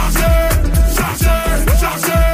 charger charger charger Meme Sound Effect